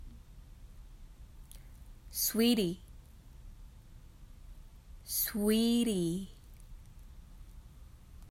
発音音声